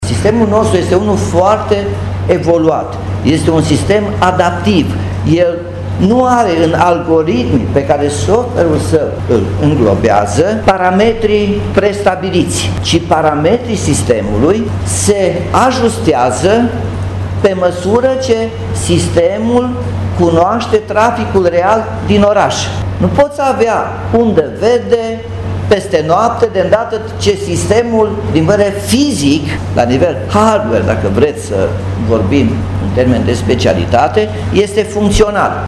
Primarul Nicolae Robu a declarat că radarele de la pasajul Michelangelo sunt funcționale, dar a mărturisit că datele sunt doar informative, șoferii neputând fi amendați pentru că nu există cadru legal care să permită acest lucru.